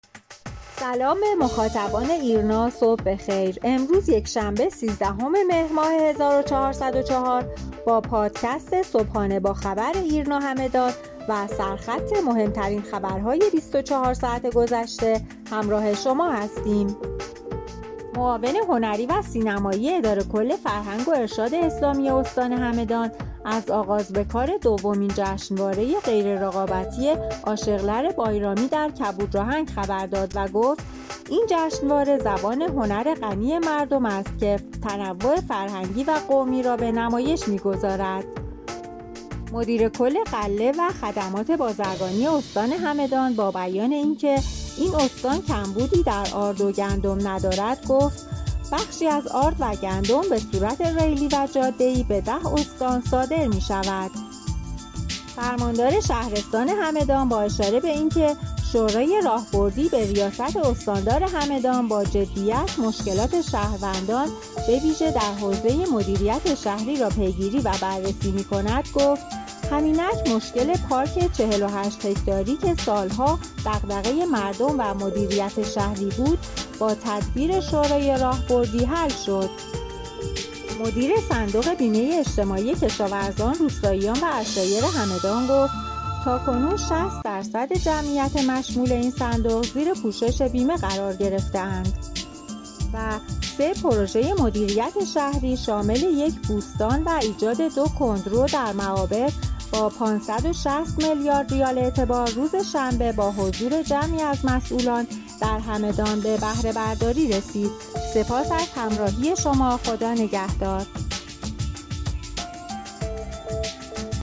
همدان- ایرنا- صادرات آرد و گندم به صورت ریلی و جاده ای به ۱۰ استان، پوشش ۶۰ درصدی بیمه برای جمعیت روستایی همدان، بهره‌برداری از سه پروژه شهری در همدان با ۵۶۰ میلیارد ریال اعتبار و برگزاری جشنواره موسیقی عاشیقلار مهم ترین اخبار شبانه روز گذشته استان همدان بود. خبرنامه صوتی (پادکست) صبحانه با خبر ایرنا همدان را هر روز ساعت هشت صبح دنبال کنید.